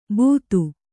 ♪ būtu